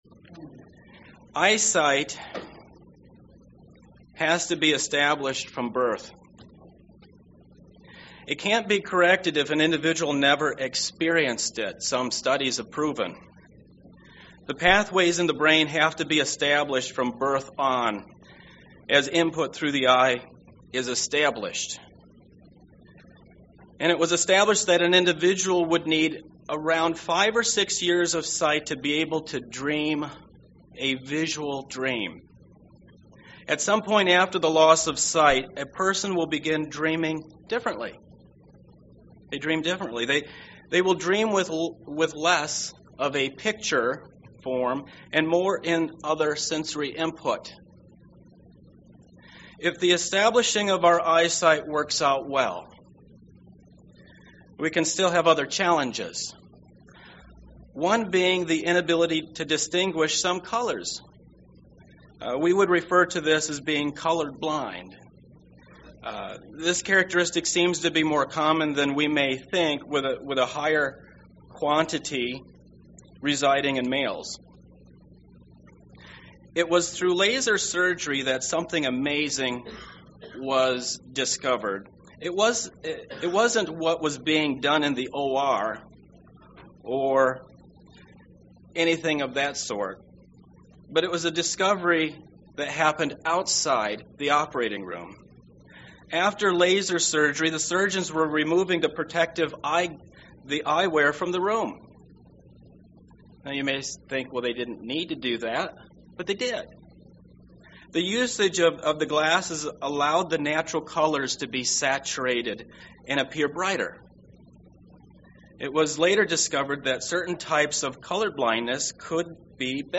Given in Milwaukee, WI
UCG Sermon Pentecost Holy Spirit early church Church eye eyesight vision Studying the bible?